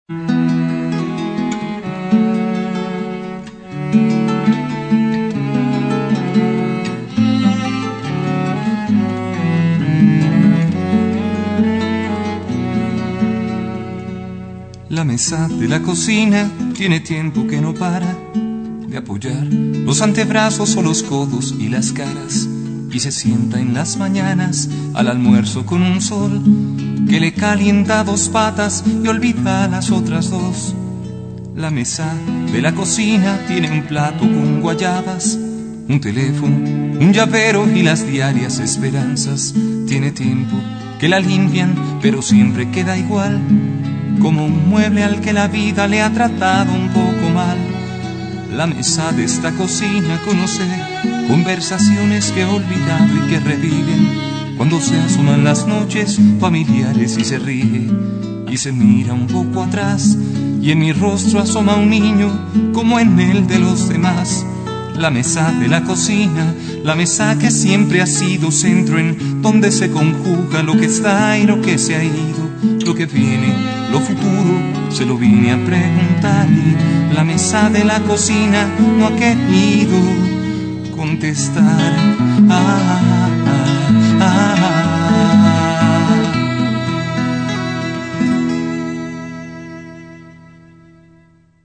Trova.